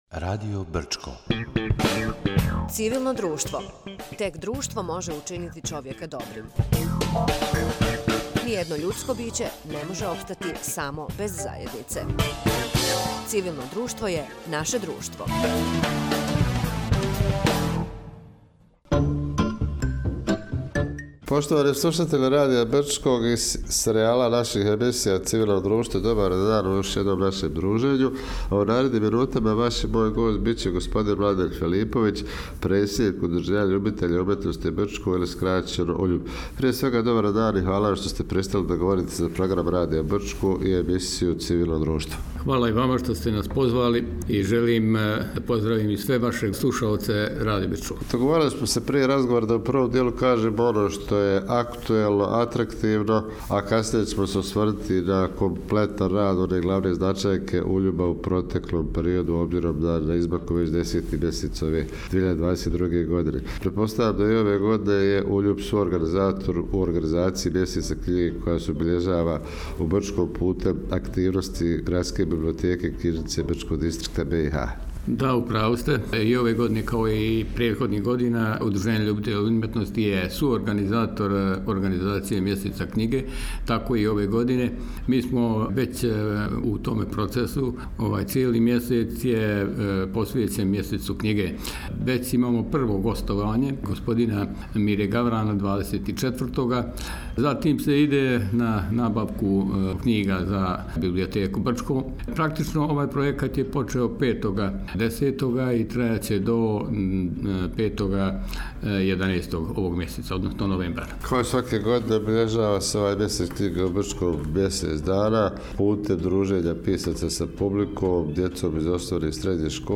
Gost emisije “Civilno društvo”